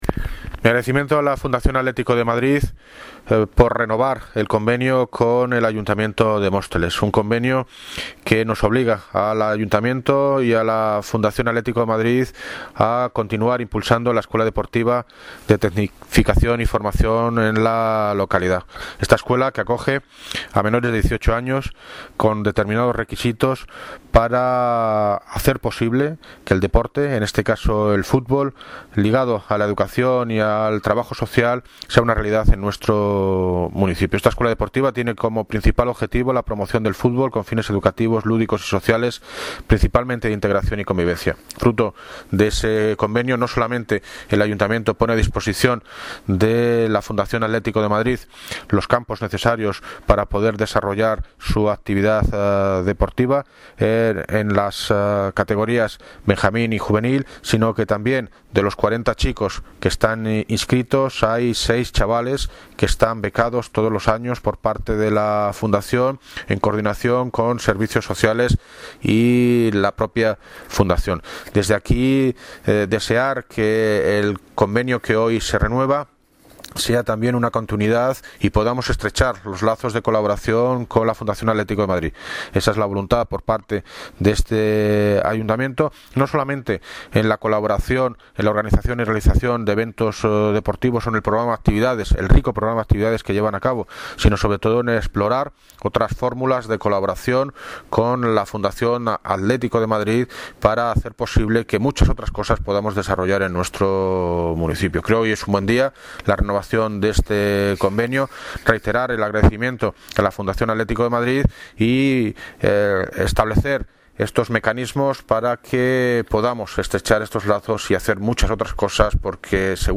Audio - David Lucas (Alcalde de Móstoles) Sobre Firma Convenio Fundación Atletico de Madrid
Audio - David Lucas (Alcalde de Móstoles) Sobre Firma Convenio Fundación Atletico de Madrid.mp3